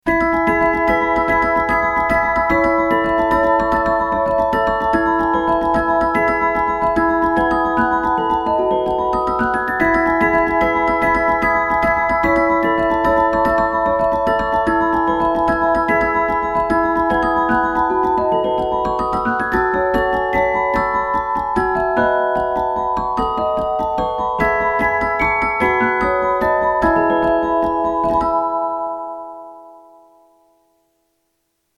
Spielwerk: 36-stimmig
Filmmelodie